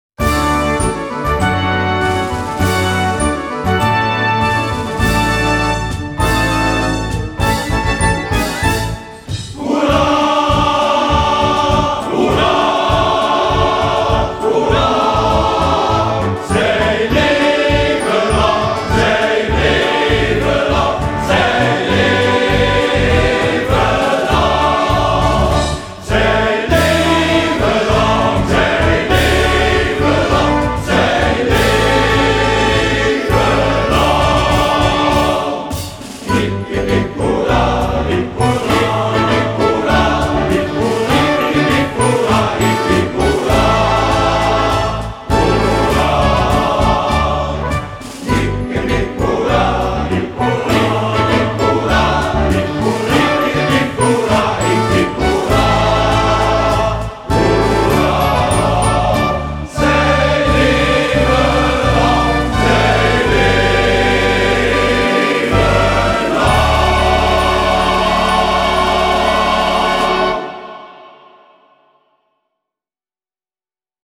Het feestlied dat Geleens Mannenkoor Mignon zingt voor jarigen, jubilarissen en andere feestelingen.
Zij leven lang Partituur Alleen muziekband Muziekband en partituur Studio opname maart 2025 terug naar Oefenpagina
Toast+opname+studio.mp3